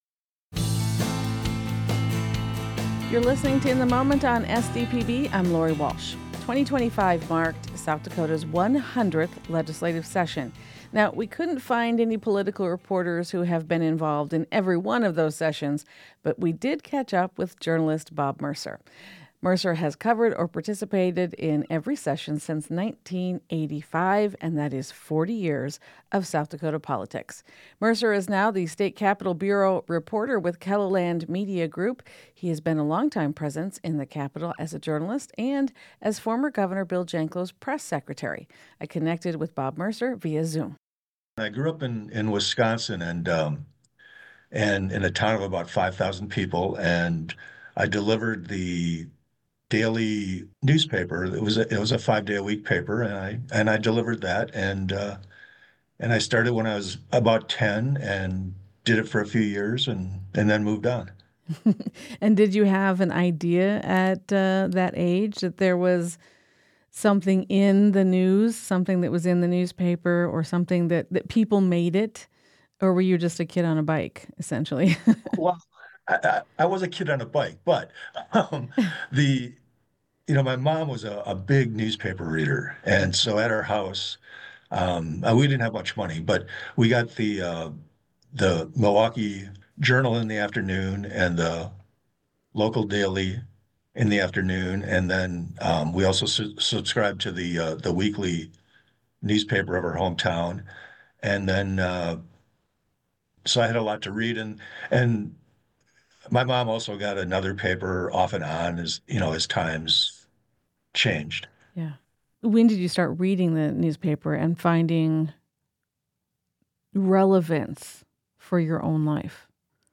discusses the top political headlines with journalists, former legislators, and political scientists from around the state